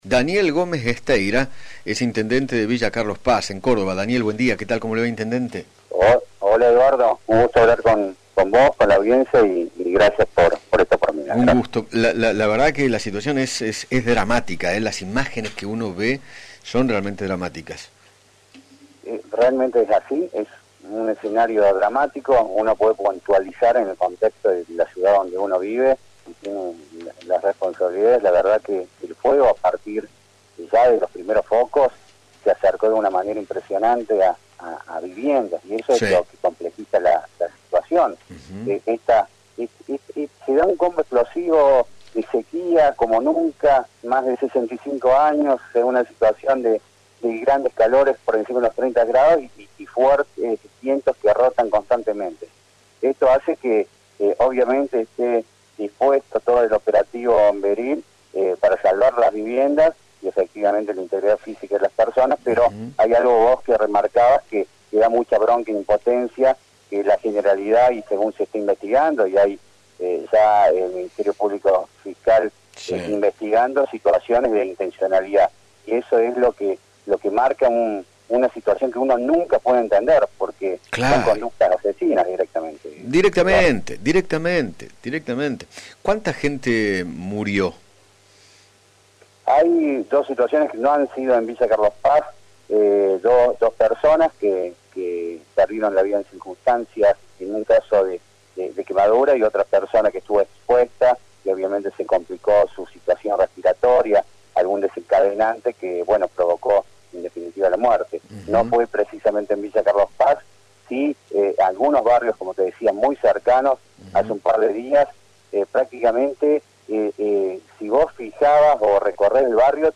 Daniel Gómez Gesteira, intendente de Villa Carlos Paz, conversó con Eduardo Feinmann acerca de los incendios en aquella provincia y manifestó que “se está investigando si fue intencional”.